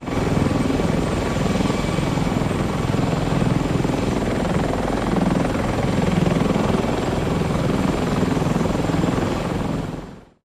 CH-53 Sea Stallion | Sneak On The Lot